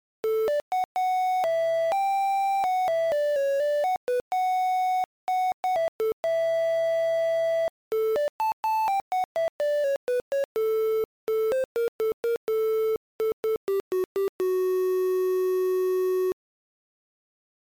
Unused music